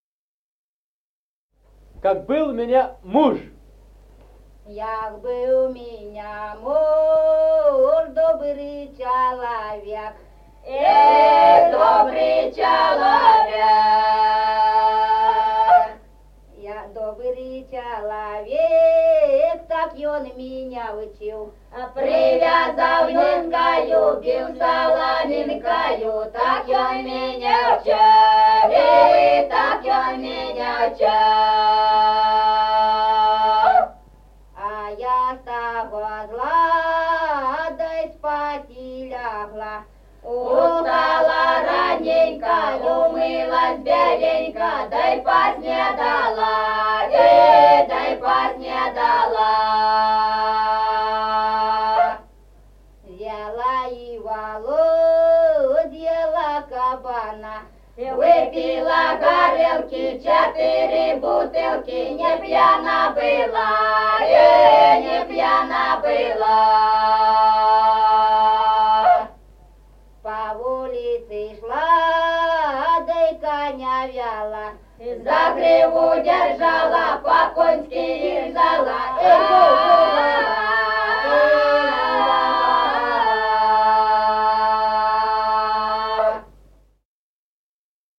Народные песни Стародубского района «Як быв ў меня муж», городская.
(подголосник)
(запев).